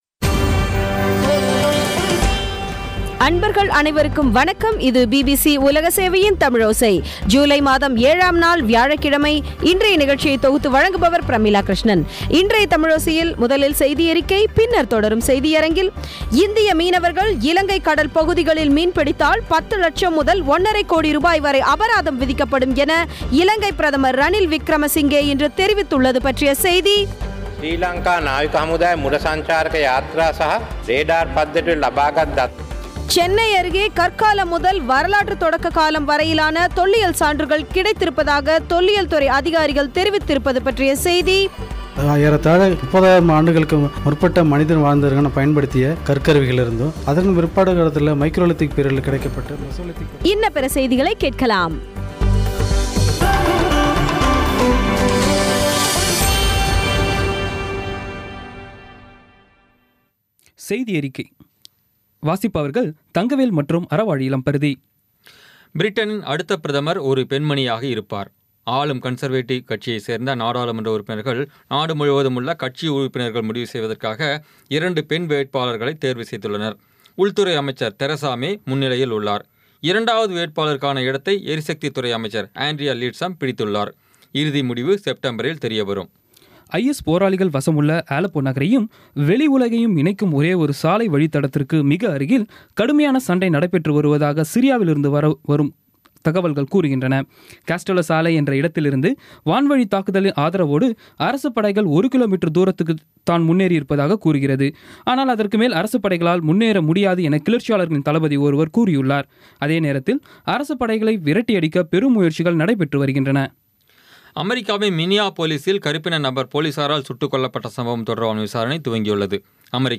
இன்றைய தமிழோசையில், முதலில் செய்தியறிக்கை பின்னர் தொடரும் செய்தியரங்கில், இந்திய மீனவர்கள் இலங்கை கடல் பகுதிகளில் மீன்பிடித்தால் 10 லட்சம் முதல் 1.5கோடி ரூபாய் வரை அபராதம் விதிக்கப்படும் என்று இலங்கை பிரதமர் ரணில் விக்ரமசிங்கே இன்று தெரிவித்துள்ளது பற்றிய செய்தி சென்னை அருகே பெரும்புதூர் கிராமத்தில் கற்காலம் முதல் வரலாற்றுத் தொடக்க காலம் வரையிலான தொல்லியல் சான்றுகள் கிடைத்திருப்பதாக தொல்லியல் துறை அதிகாரிகள் தெரிவித்திருப்பது பற்றிய செய்தி இன்ன பிற செய்திகளை கேட்கலாம்